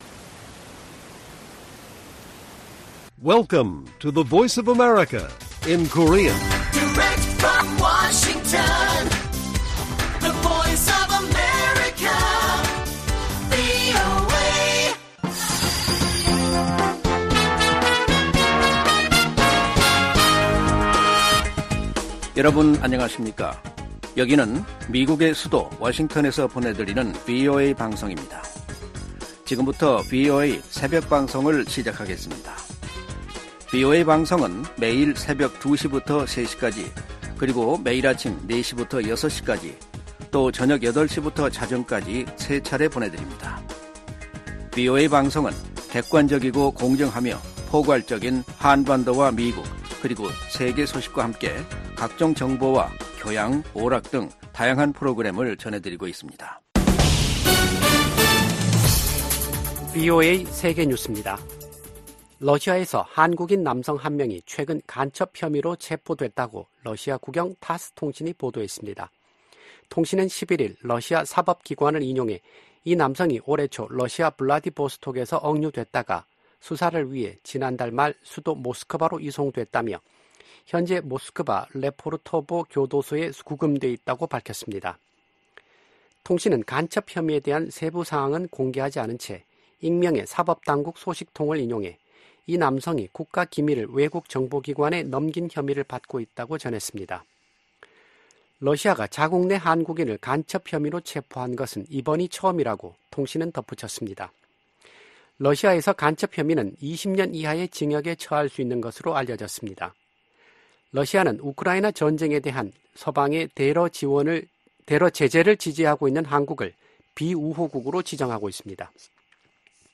VOA 한국어 '출발 뉴스 쇼', 2024년 3월 12일 방송입니다. 미국은 전제 조건 없이 대화에 열려 있지만 북한은 관심 징후를 전혀 보이지 않고 있다고 미 국무부가 지적했습니다. 미국과 한국의 북 핵 대표들의 직책 또는 직급이 변화를 맞고 있습니다. 한반도 비핵화 과정의 중간 조치를 고려할 수 있다는 미 당국자들의 언급에 전문가들은 북한 핵 역량 검증의 어려움을 지적했습니다.